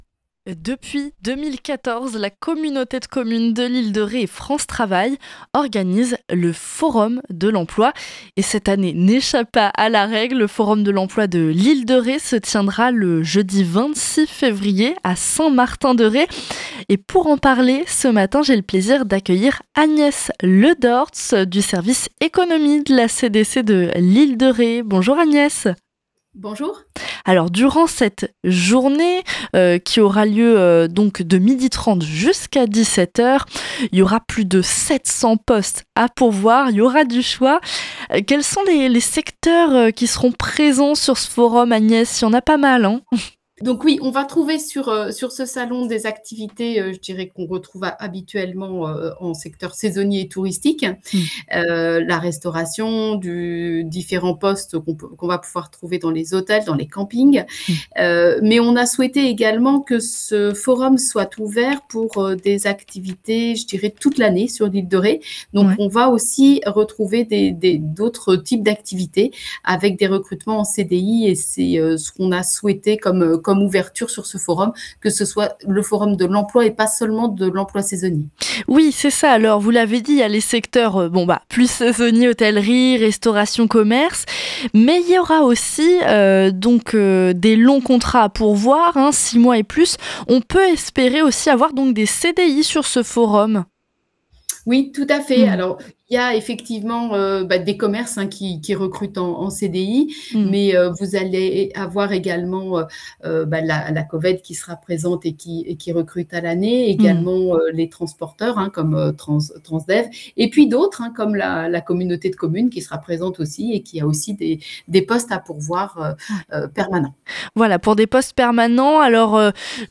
L’interview est à retrouver ci-dessous.